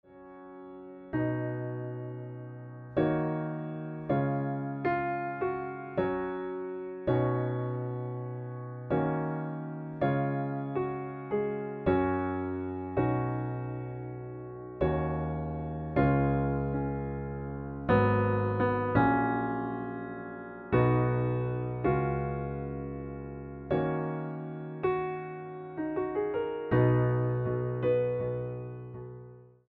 Partitura Piano (acompañamiento) con cifrado de acordes